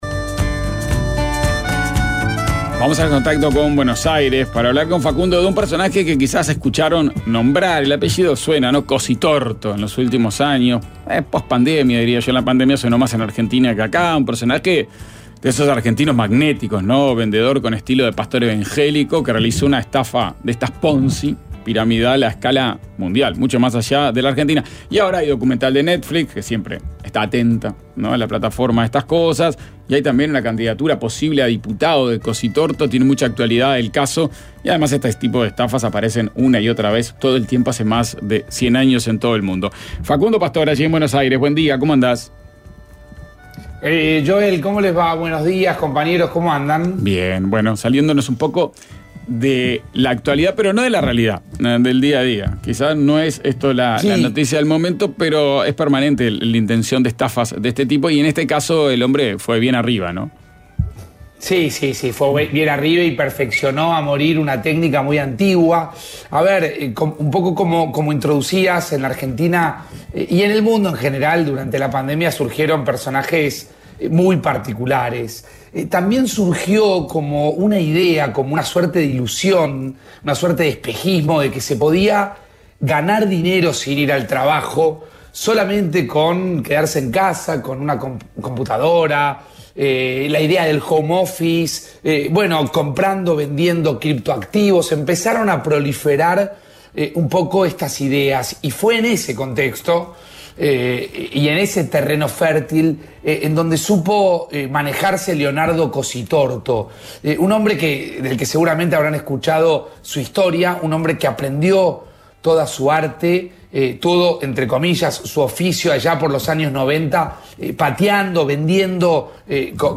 Contacto con el colaborador desde Buenos Aires.